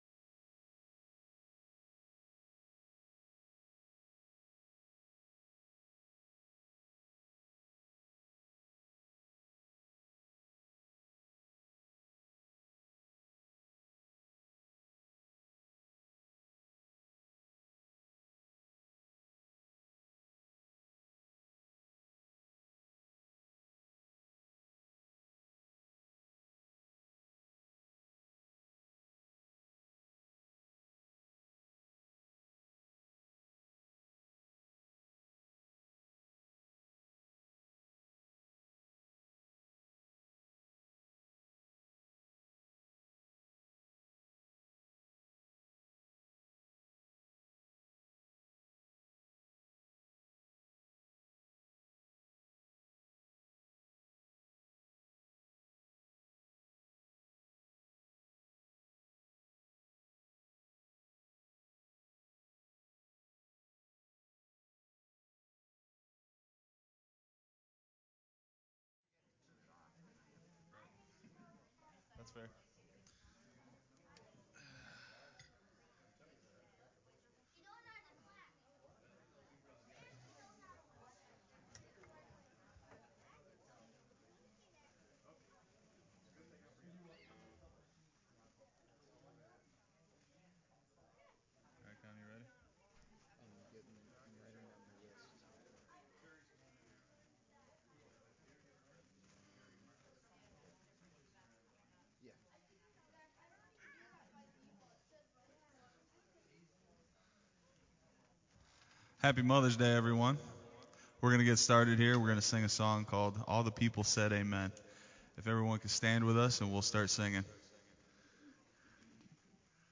May 8th 2022 Worship
Praise Worship